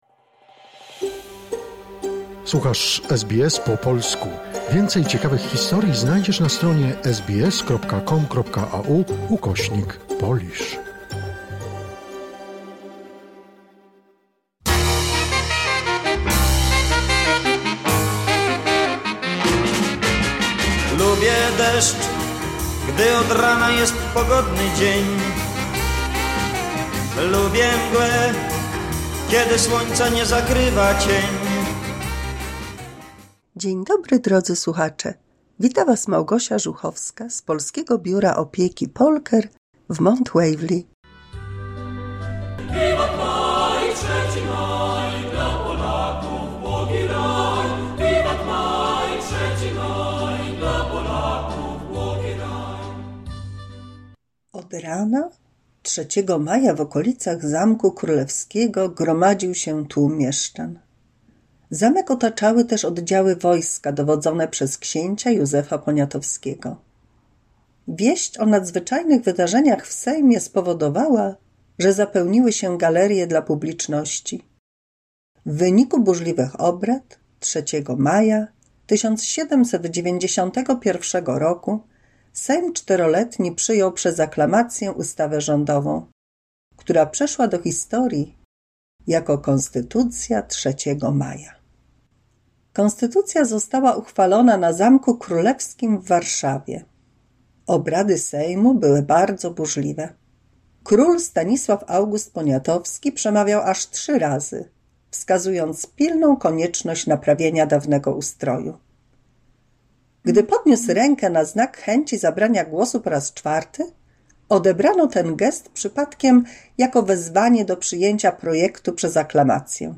W 204 mini słuchowisku dla polskich seniorów, o Konstytucji 3 Maja...